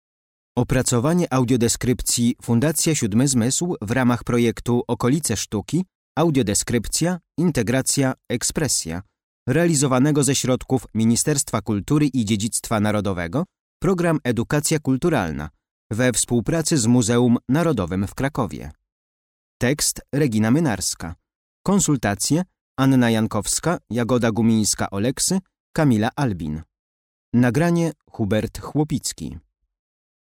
Audiodeskrypcja - Dom Jana Matejki